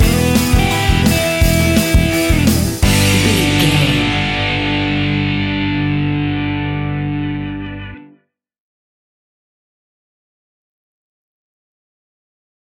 Fast paced
Aeolian/Minor
pop rock
fun
energetic
uplifting
guitars
bass
drums
piano
organ